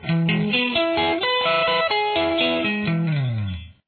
This is an E major arpeggio using the G style pattern.
G_style_arpeggio.mp3